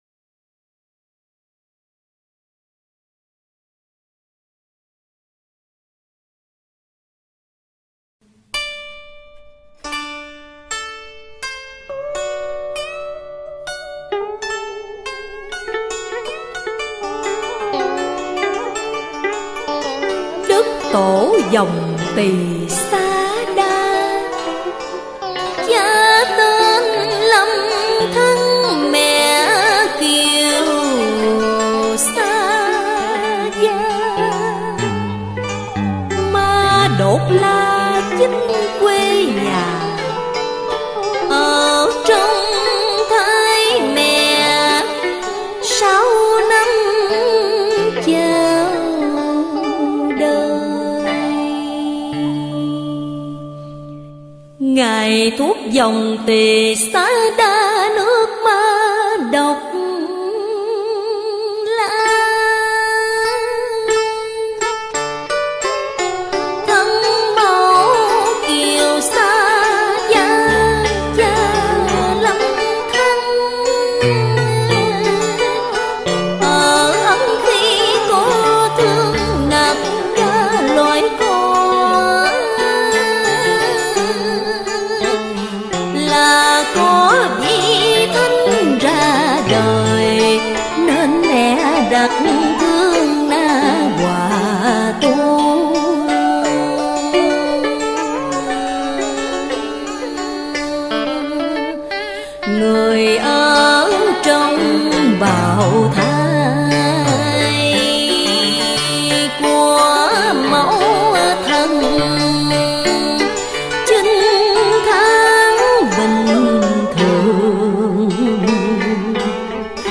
Thể loại: Tân cổ